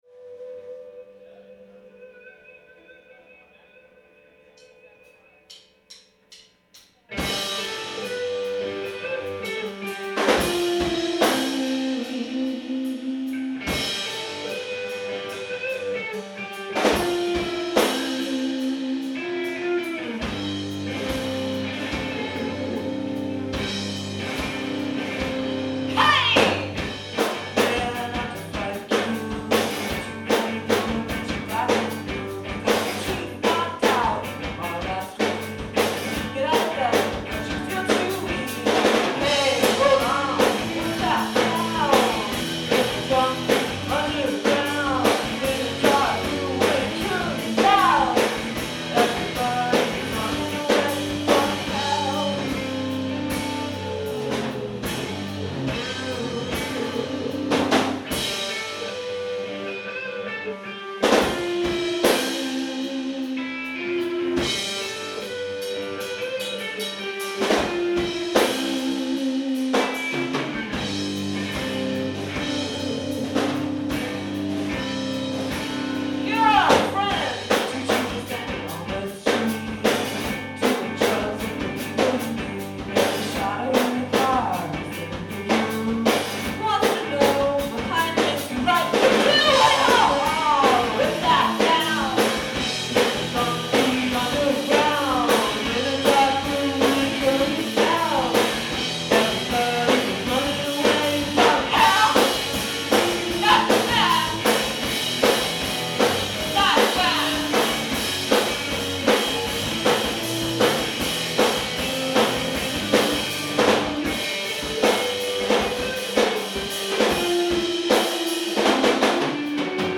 Live at TT the Bears
Cambridge, Massachusetts